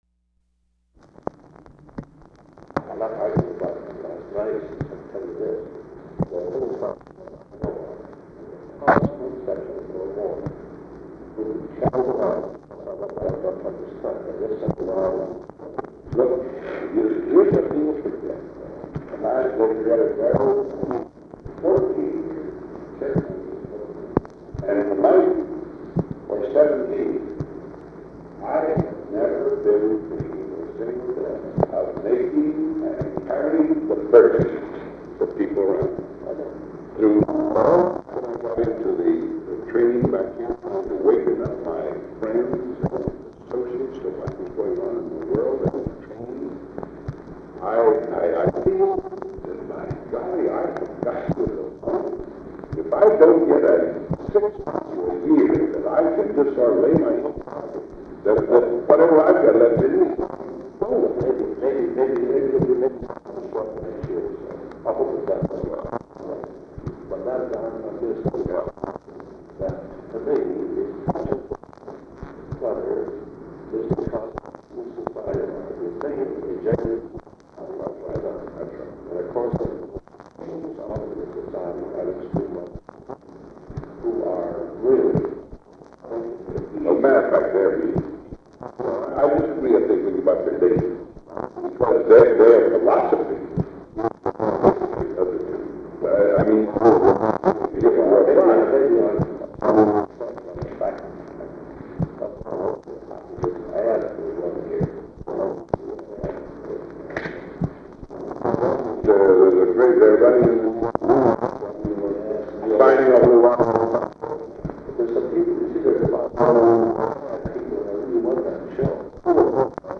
Recording begins with conversation already in progress. DDE is speaking of the political situation in the country and difficulties he is having with Congress. DDE brings up the subject of a Special Assistant or a Cabinet rank official for disarmament.
Secret White House Tapes